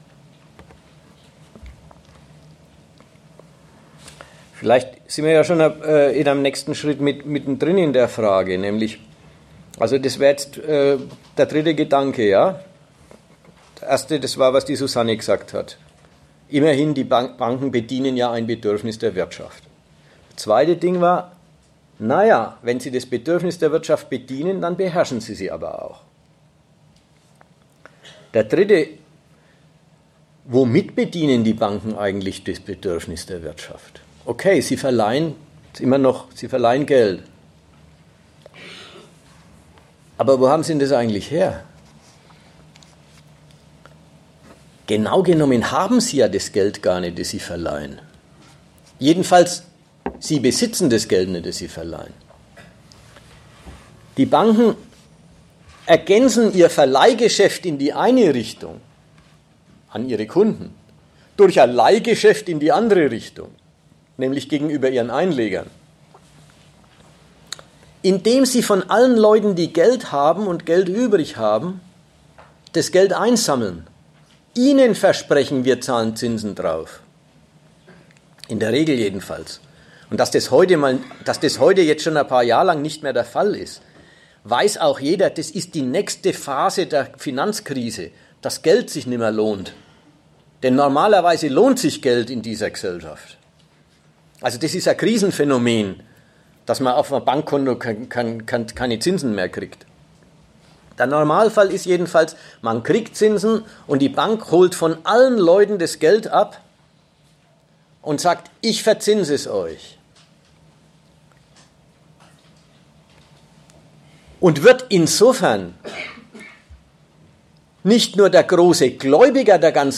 Das Finanzkapital (Buchvorstellung) - Schulden: der wahre Reichtum des Kapitalismus | Argument und Diskussion